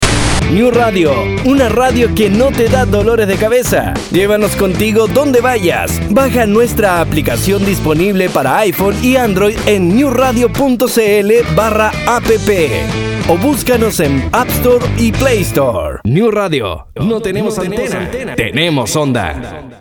Dubbing Actor - Voiceover - Narrator - Voiceacting - and more
chilenisch
Sprechprobe: Sonstiges (Muttersprache):